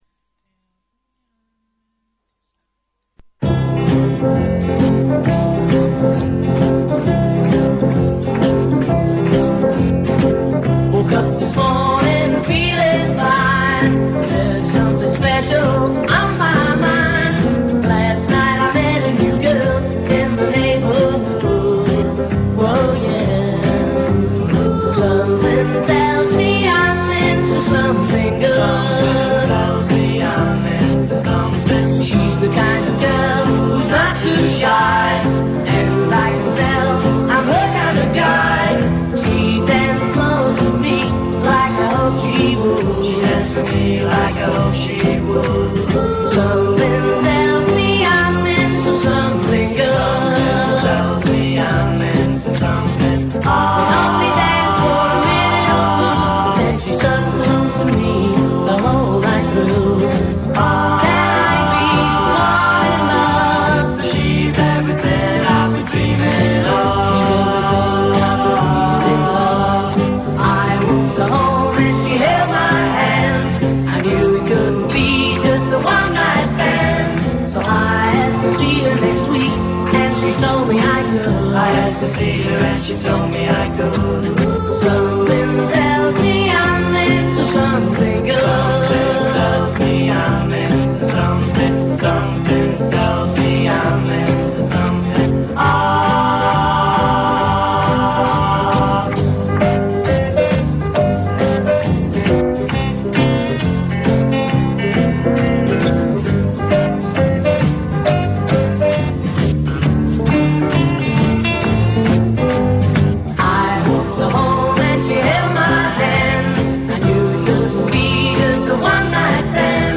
THESE SOUNDS ARE IN REALAUDIO STEREO!